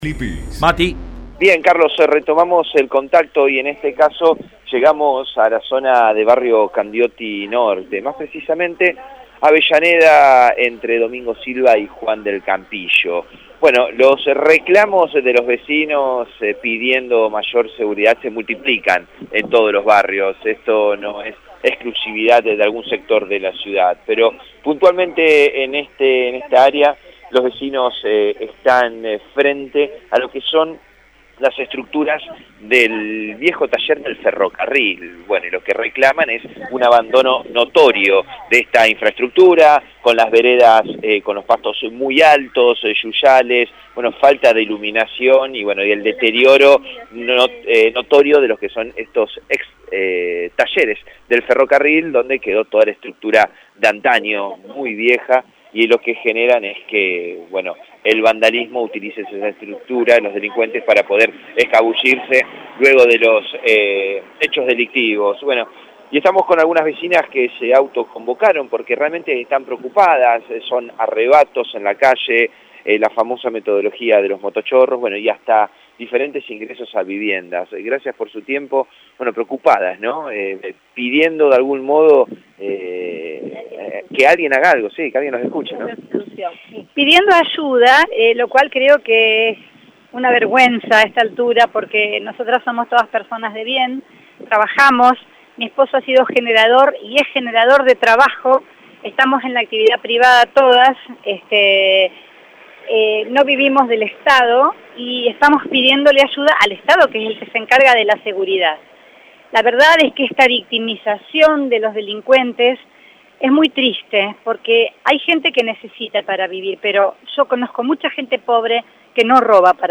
Congregados en la intersección de Avellaneda y Juan del Campillo, un nutrido grupo de vecinos autoconvocados de barrio Candioti Norte reclamaron acciones concretas para amedrentar a los delincuentes que los hostigan todos los días.
«Entran cuando no estamos, nos sentimos vigialdos», relatan con miedo al móvil de la radio.
Vecinos autoconvocados: